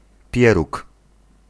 Ääntäminen
Ääntäminen US Haettu sana löytyi näillä lähdekielillä: englanti Käännös Ääninäyte Substantiivit 1. pieróg {m} Dumpling on sanan dumple partisiipin preesens. Määritelmät Substantiivit A ball of dough that is cooked and may have a filling and/or additional ingredients in the dough.